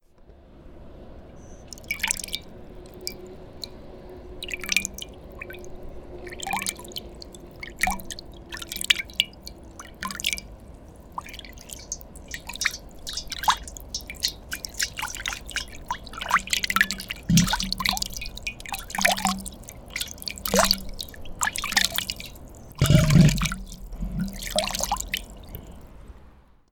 우주_파동.mp3